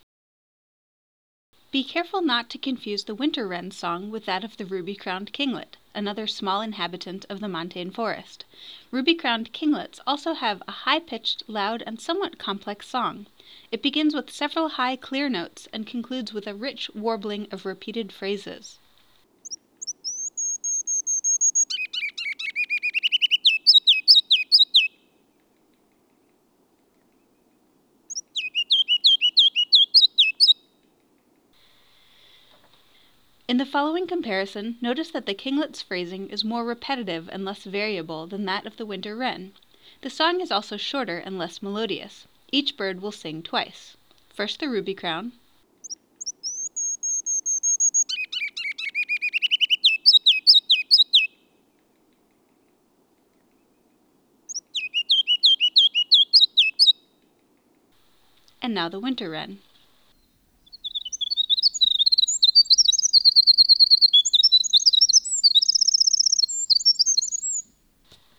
Practice identifying songs and calls of Mountain Birdwatch focal species and similar spruce-fir birds.
Winter-Wren-vs-Ruby-crowned-Kinglet.wav